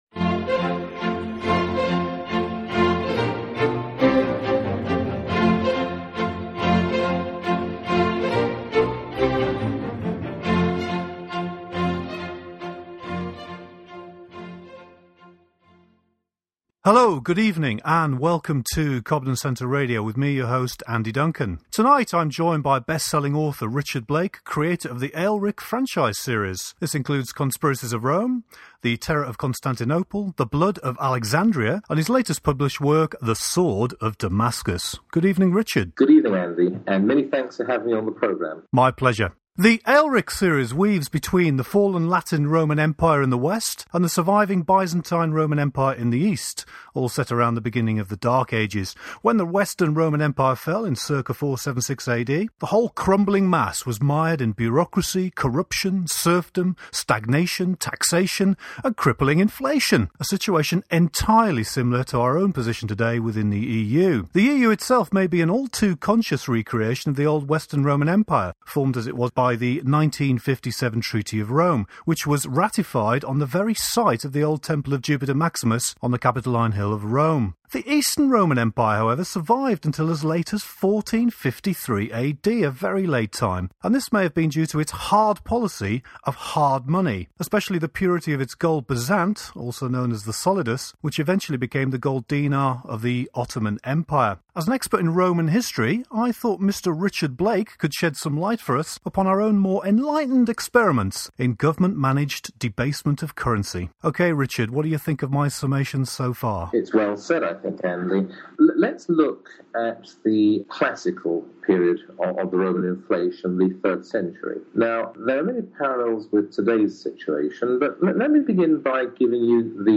19-ccr_show_19_inflation_roman_empire.mp3